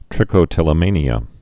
(trĭkō-tĭlə-mānē-ə, -mānyə)